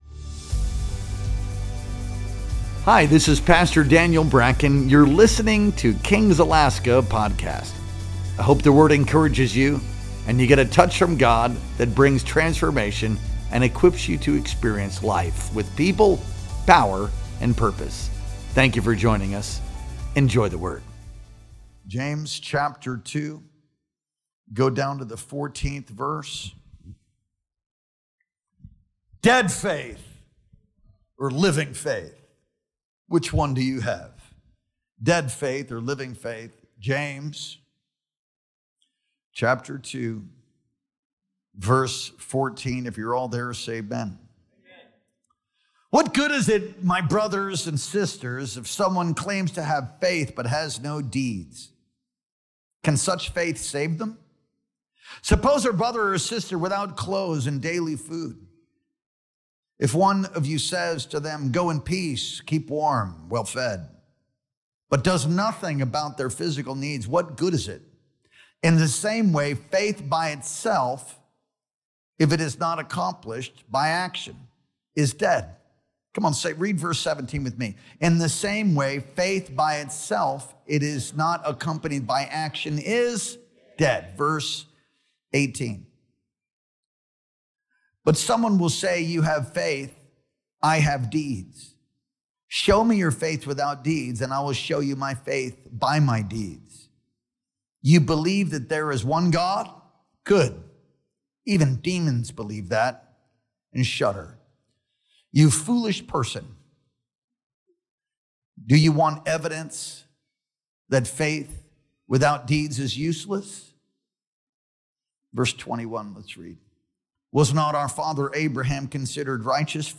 Our Sunday Night Worship Experience streamed live on August 24th, 2025.